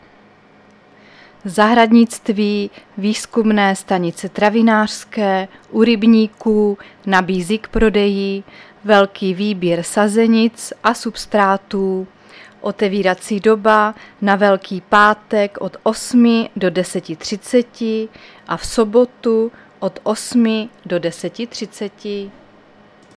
Záznam hlášení místního rozhlasu 28.3.2024
Zařazení: Rozhlas